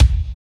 27.07 KICK.wav